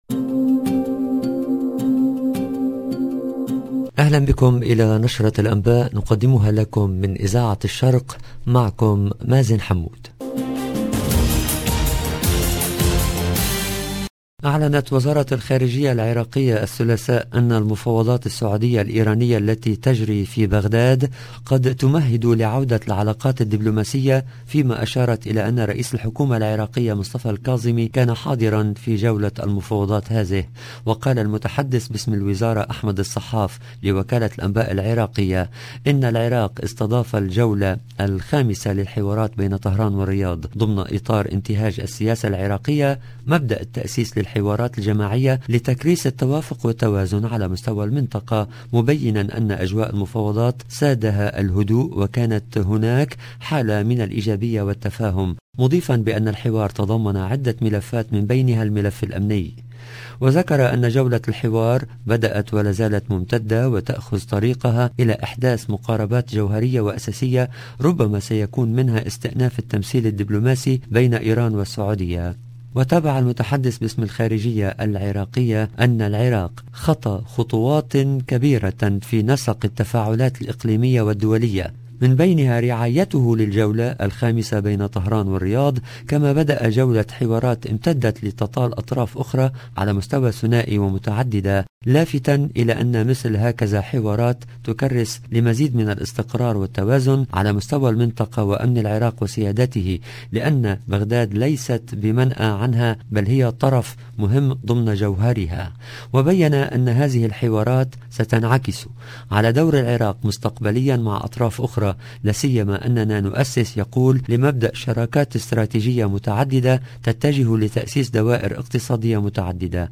LE JOURNAL EN LANGUE ARABE DU SOIR DU 26/04/22